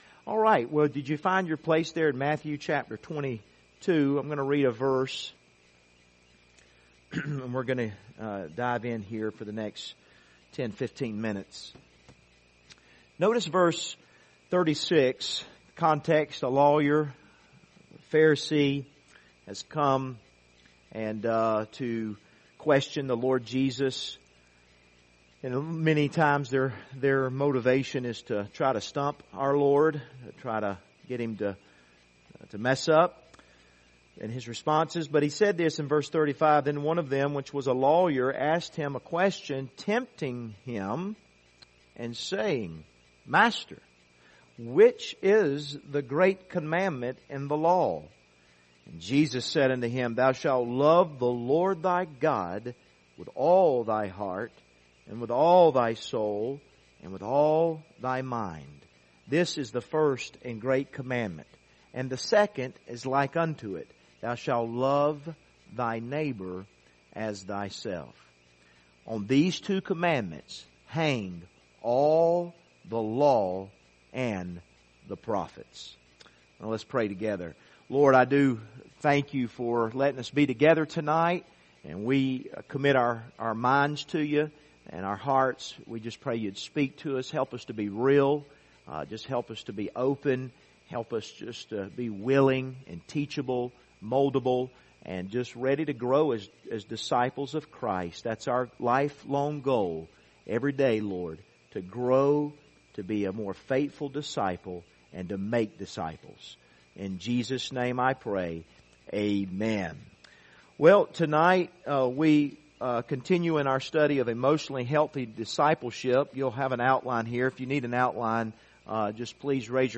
Passage: Matthew 22:36-40 Service Type: Wednesday Evening